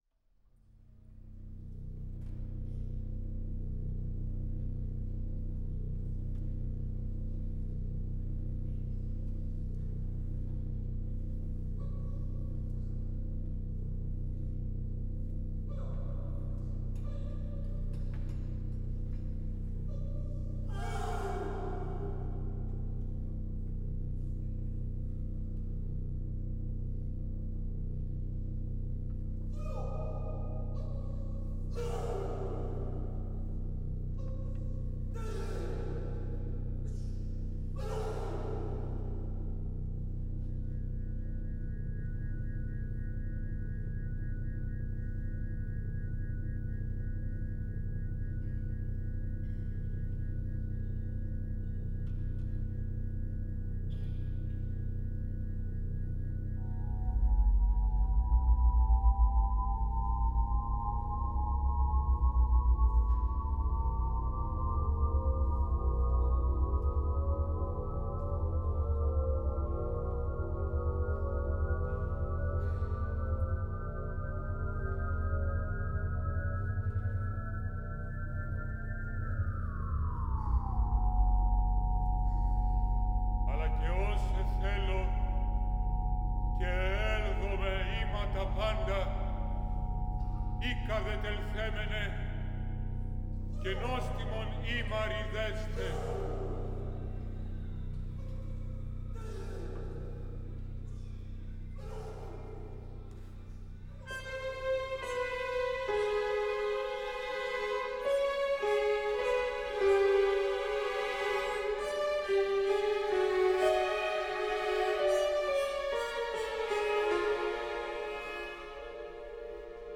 Μέγαρο Μουσικής Αθηνών, Δευτέρα 1 Απριλίου 2024